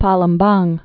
(päləm-bäng, -lĕm-)